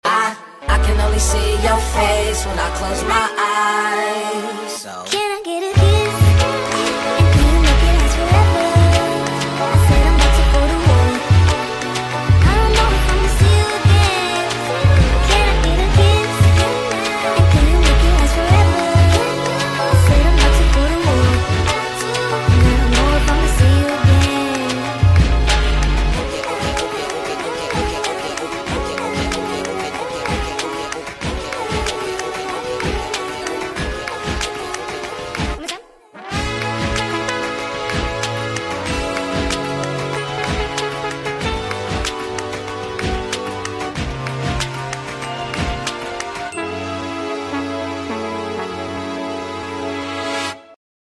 Cute romantic audio for romance lover people